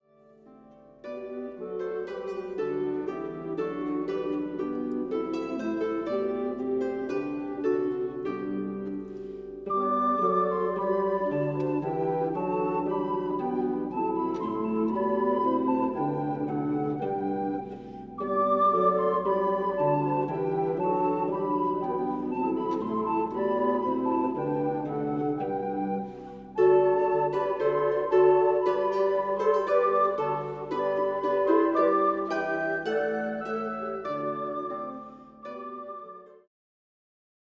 L'Harundo Ensemble'
Ai flauti si affiancano altri strumenti a corde (arpa, chitarra e mandola) e diversi strumenti a percussione.
Suoni e parole di natale: I brani più famosi della tradizione natalizia arrangiati per ensemble di flauti di bambù, alternati a letture di estratti da racconti di vari autori e generi sul Natale.